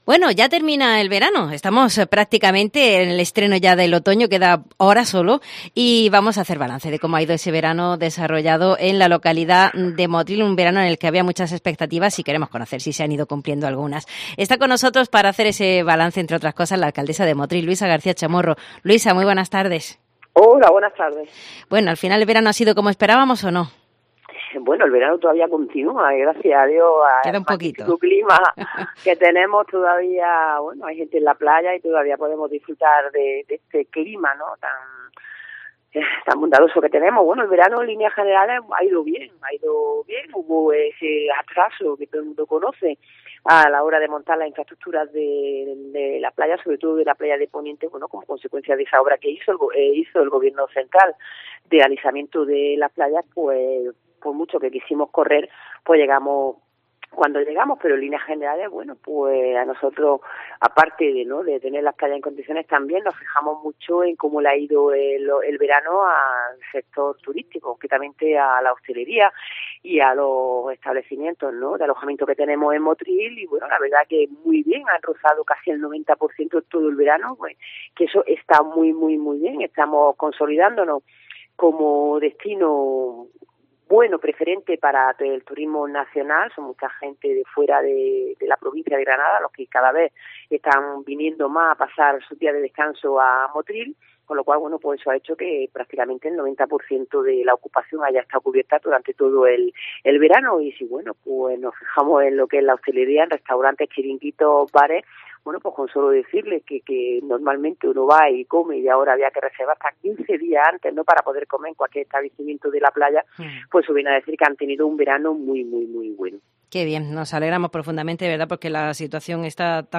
Hoy hablamos con la alcaldesa de Motril, Luisa García Chamorro con quien hemos hecho balance de este verano en la localidad en materia turística y hostelera y el balance es bastante positivo.